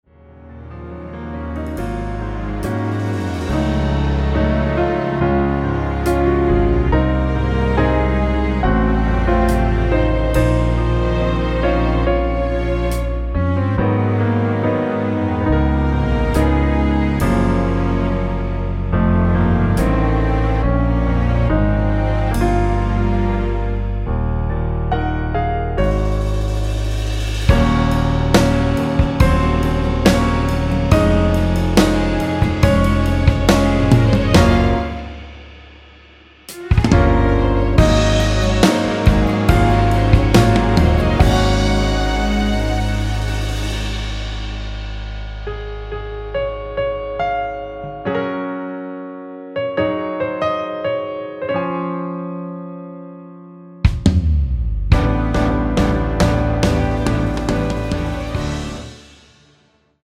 1절 “정말 고마워” 다음 후렴부분인 “밤에 울다 잠이 깼을 때”로 진행 되게 편곡하였습니다.
◈ 곡명 옆 (-1)은 반음 내림, (+1)은 반음 올림 입니다.
앞부분30초, 뒷부분30초씩 편집해서 올려 드리고 있습니다.
중간에 음이 끈어지고 다시 나오는 이유는